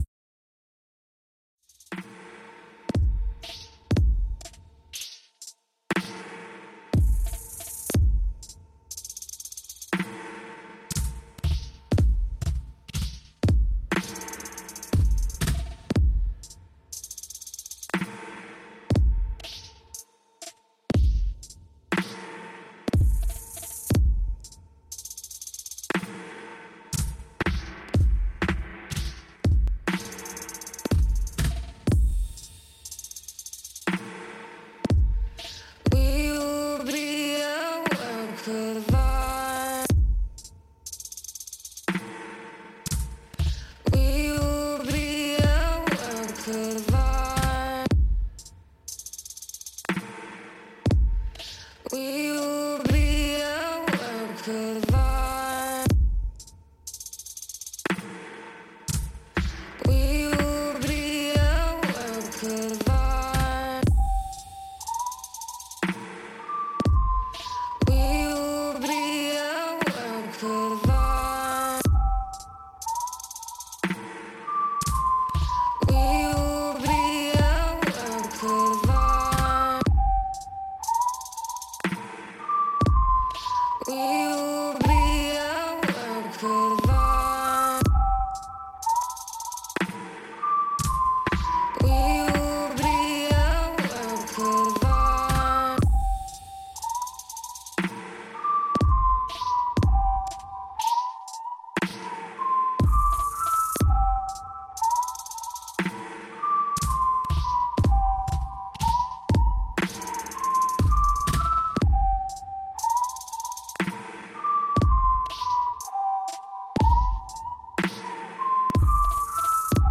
Наш первый студийный альбом
• Жанр: Хип-хоп